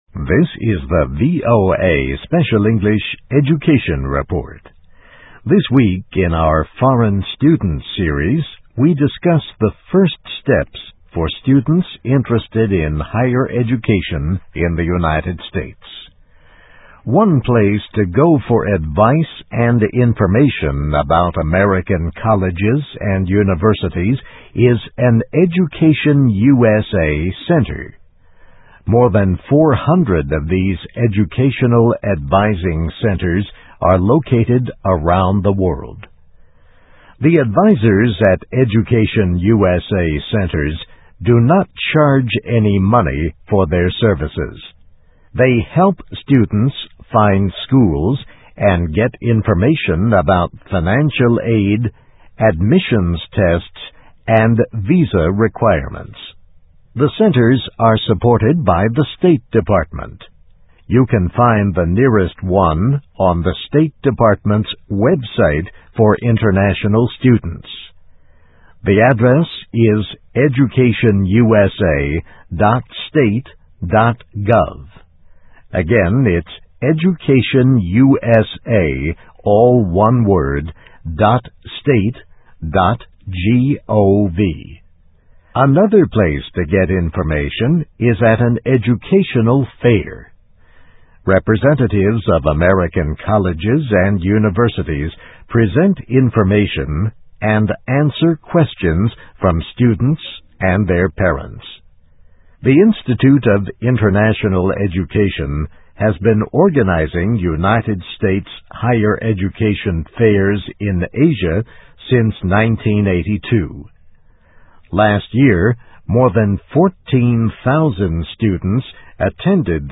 留学美国,前期准备工作|voa慢速英语下载